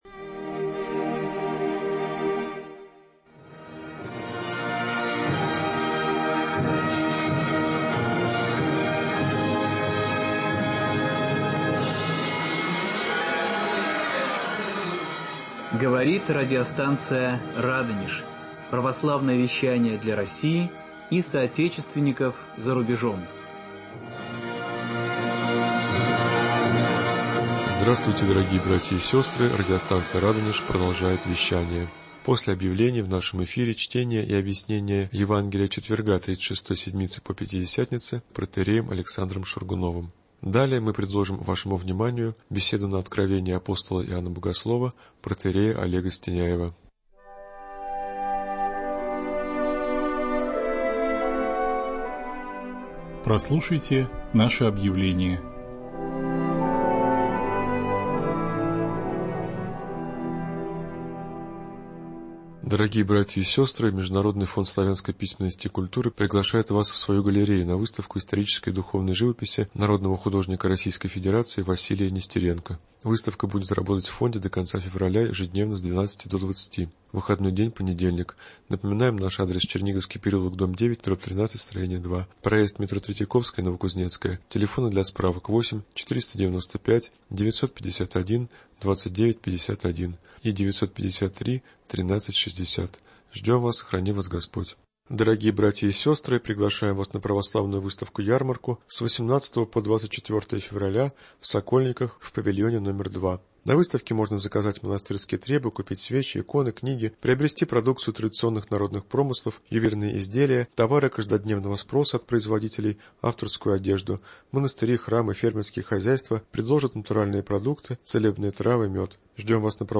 читает и разъясняет 18 главу Откровения святого Иоанна Богослова